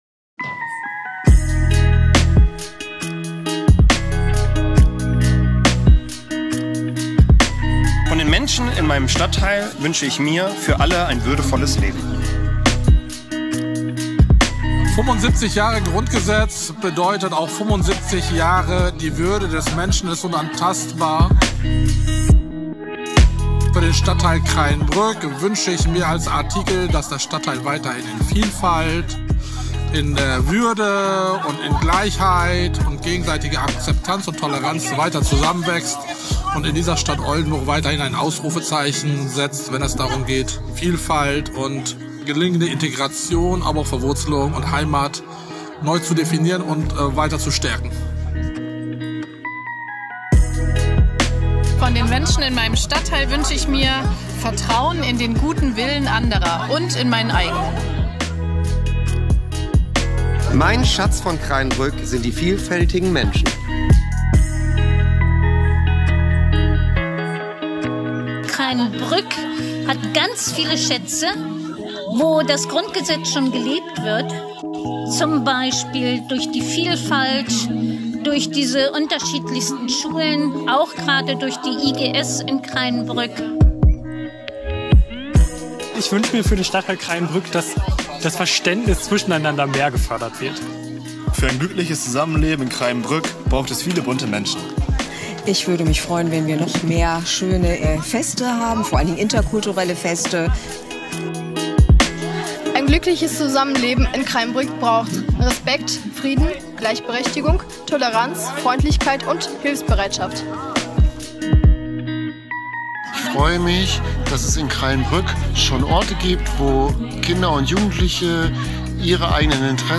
Höre Hier Kreyenbrücker Stimmen für die Grundrechte